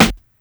Modern Original Rap Français Snare Drum Sound D Key 72.wav
Royality free snare drum tuned to the D note. Loudest frequency: 2274Hz